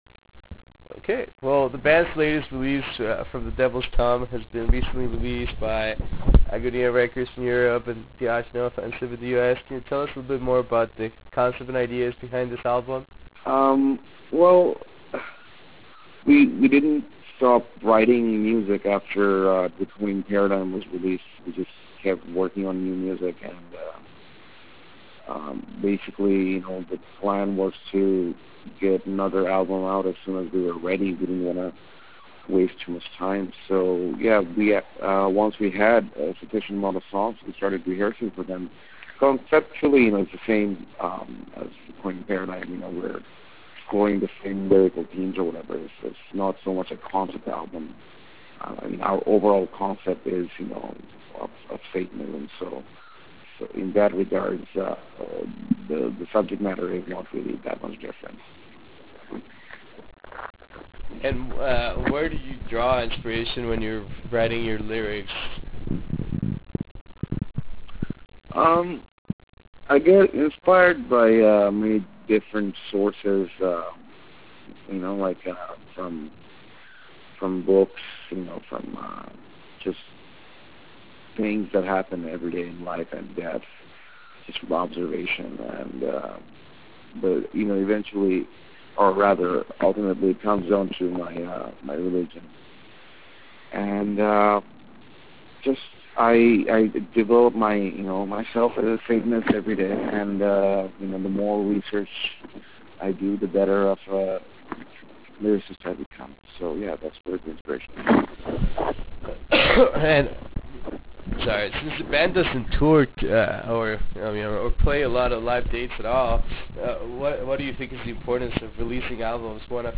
Interview with Weapon